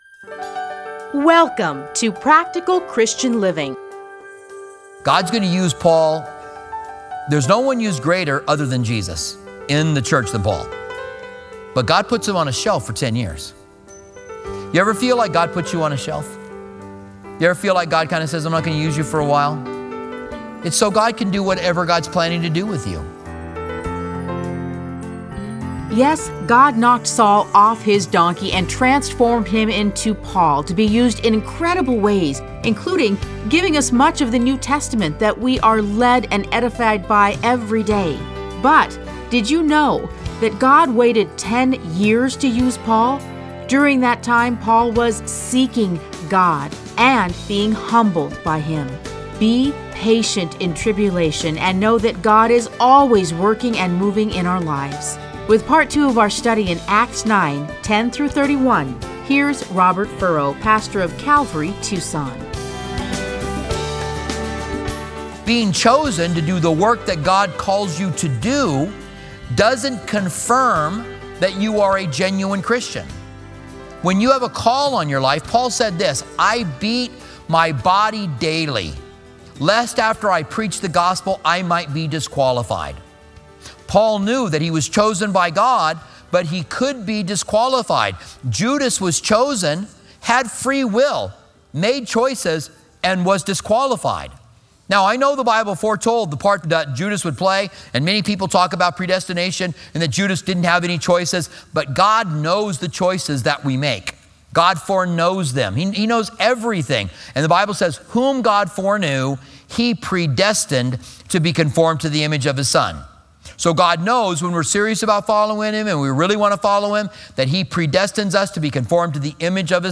Listen to a teaching from Acts 9:10-31.